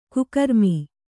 ♪ kukarmi